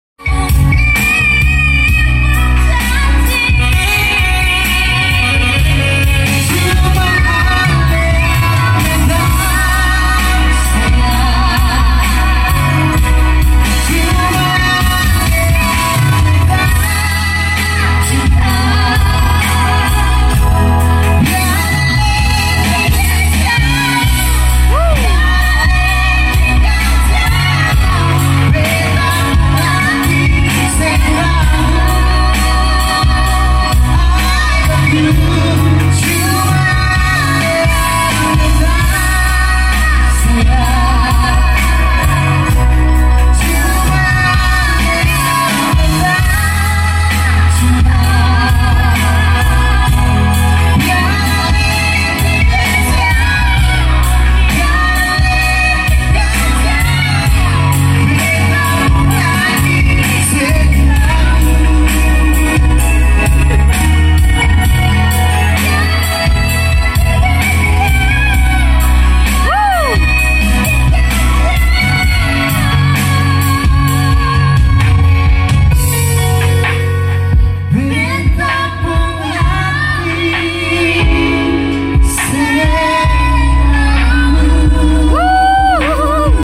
Duet